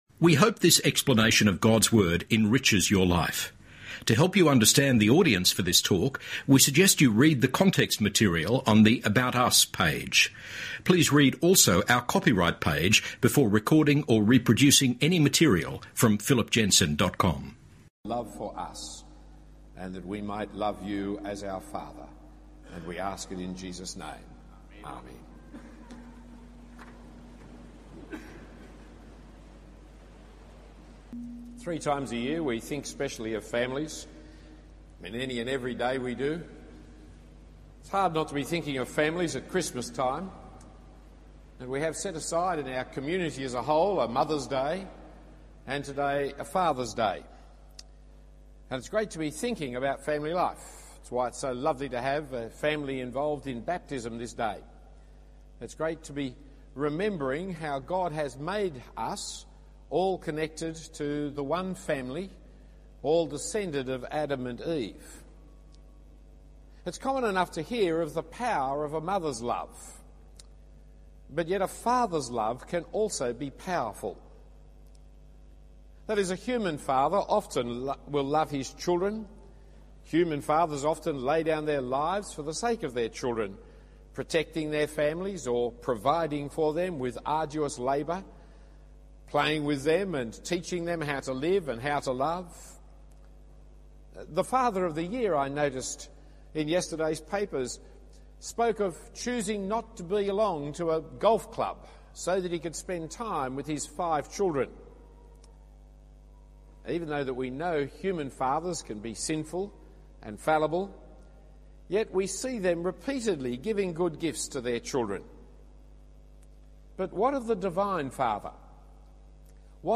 The Fathers Day sermon given at St Andrews Cathedral in 2004 in the series Father’s Day.